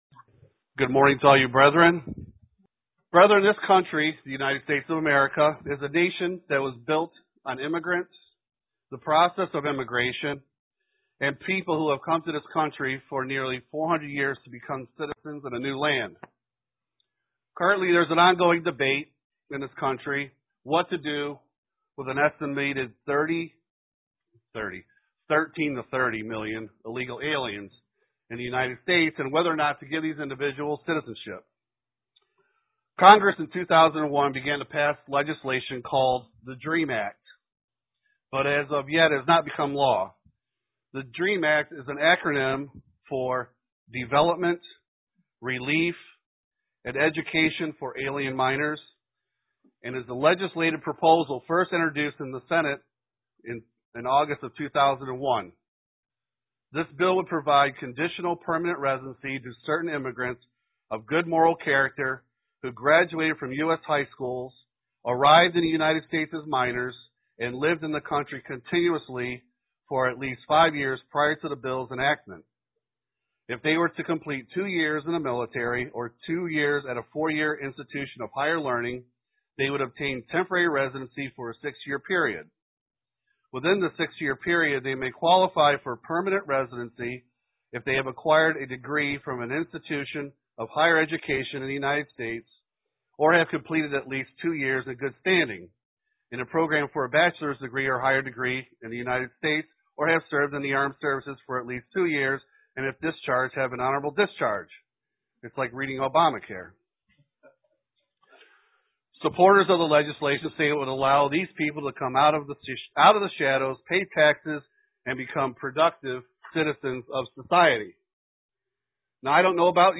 Print As members of God's Church, what it means to be citizen's of the Kingdom of God UCG Sermon Studying the bible?